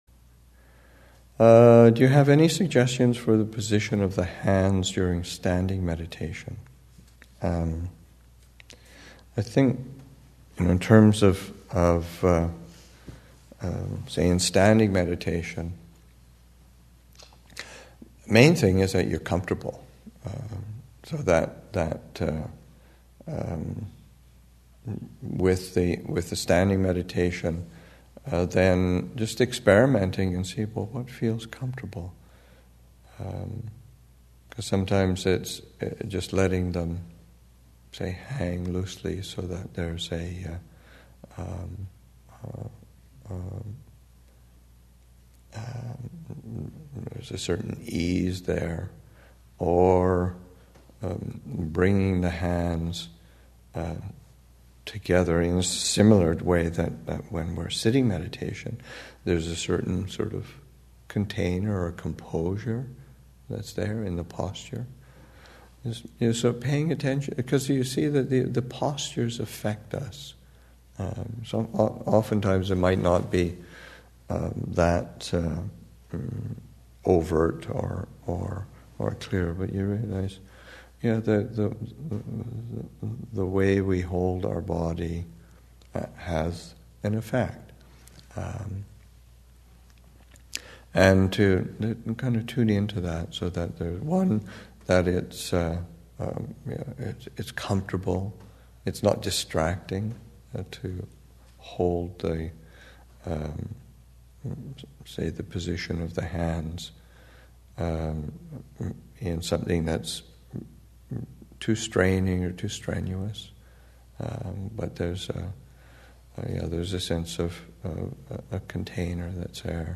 2015 Thanksgiving Monastic Retreat, Session 2 – Nov. 22, 2015